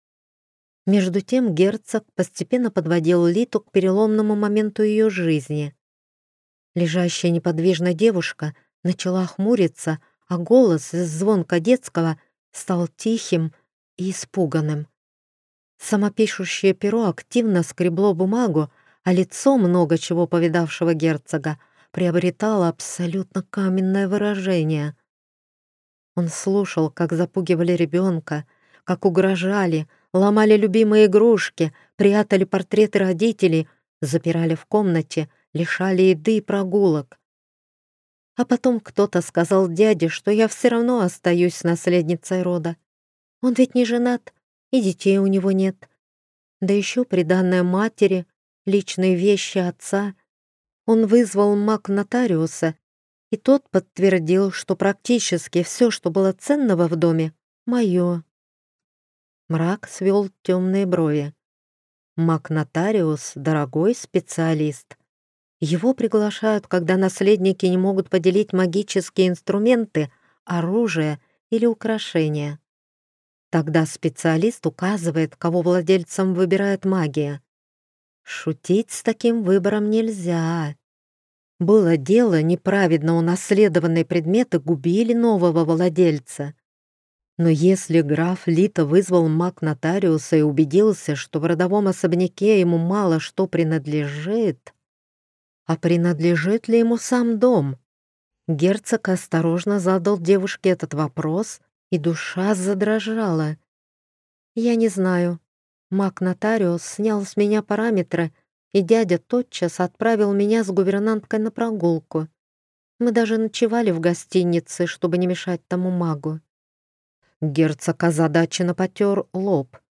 Аудиокнига «Развод, дракон и князь на выбор».